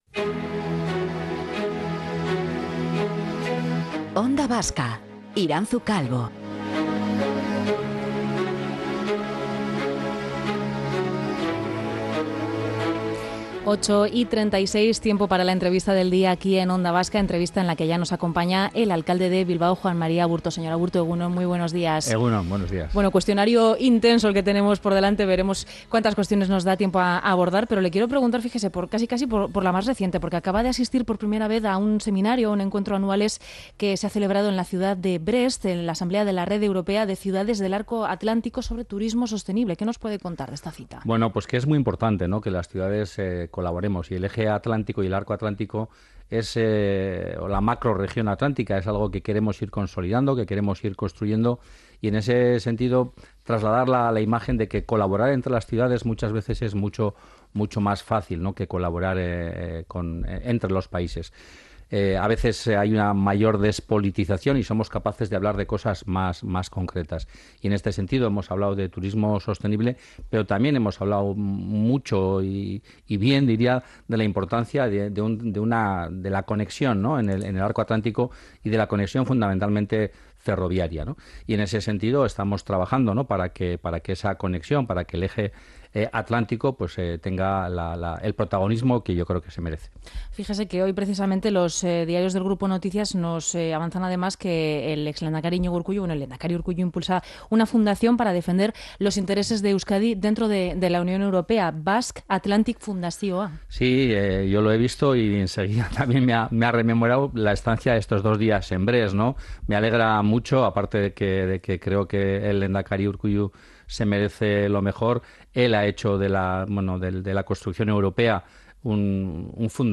Entrevistado en Onda Vasca, el alcalde de Bilbao Juan Mari Aburto ha defendido un turismo "sostenible" y en eso van a trabajar.